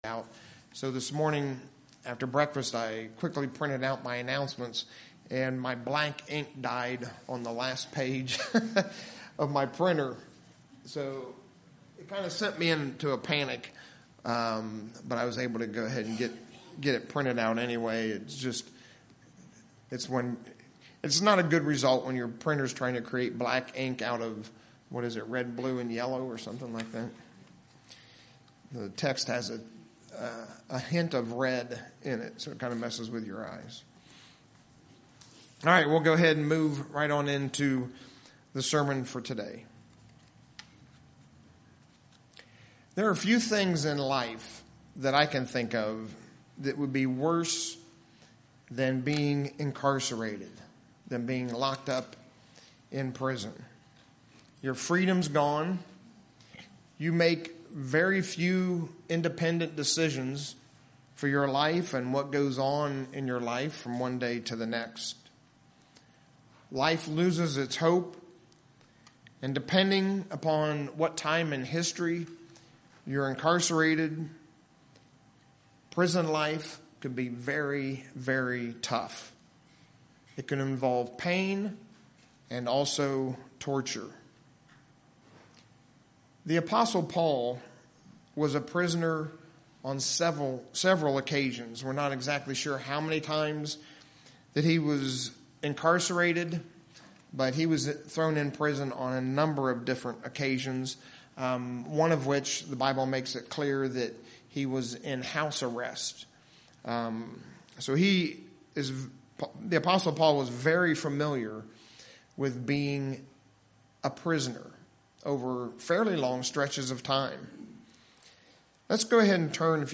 Sermon
Given in Lehigh Valley, PA